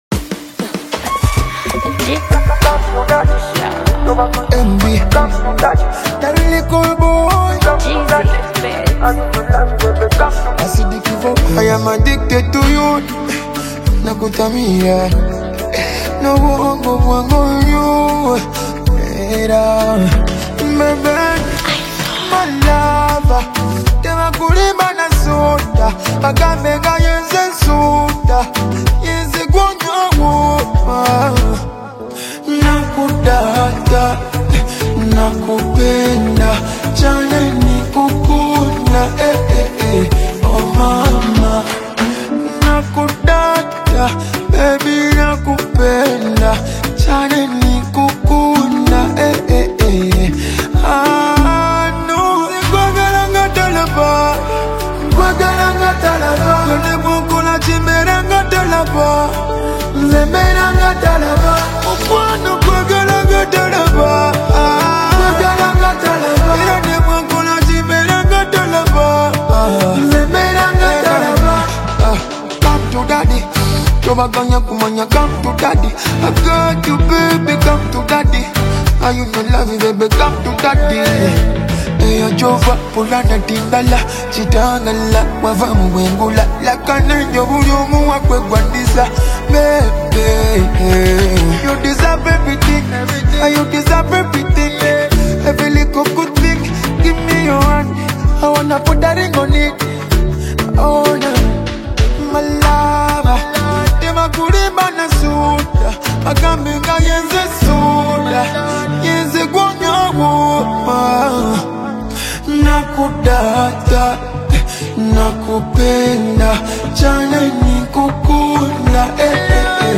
” gives off a playful yet assertive vibe.
With a catchy beat and straightforward lyrics